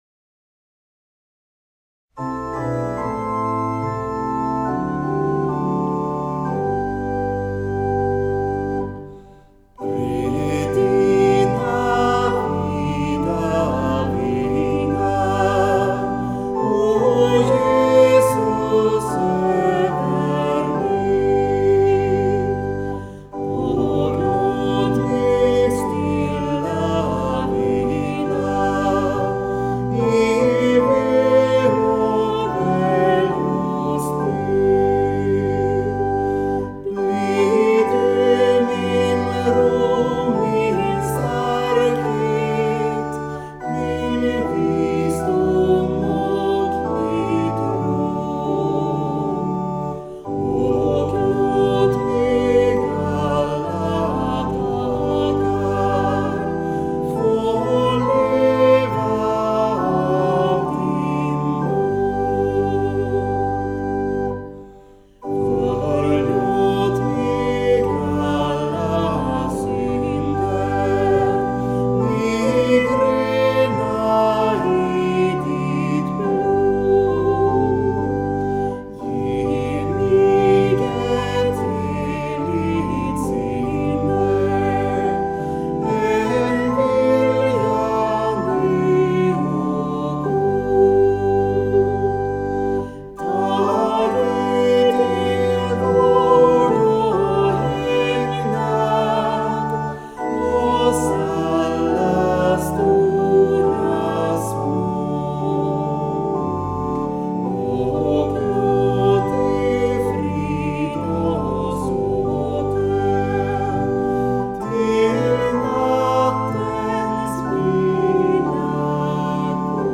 Psalmer vid begravning
Här kan du lyssna på ett urval av psalmer som församlingens musiker spelar.